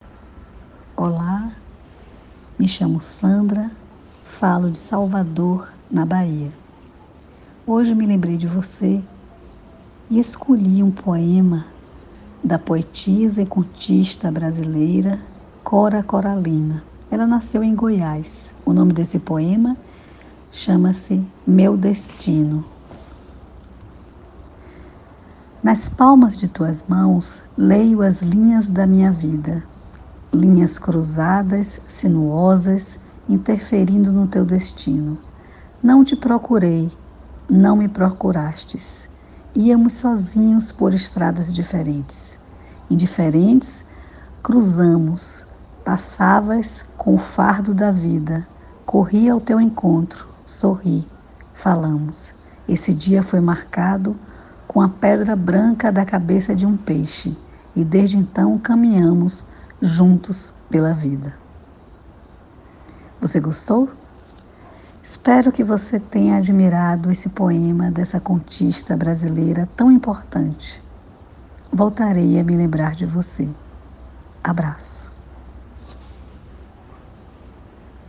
Poesia Português